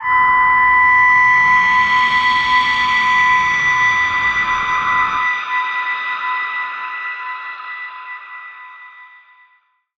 G_Crystal-B7-mf.wav